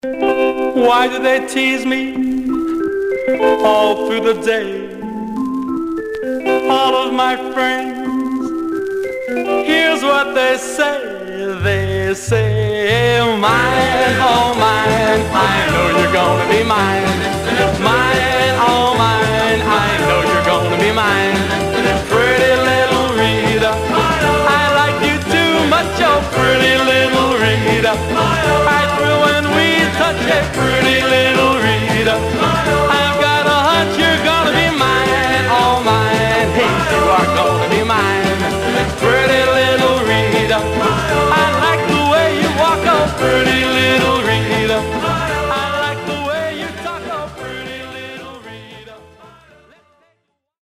Surface noise/wear
Stereo/mono Mono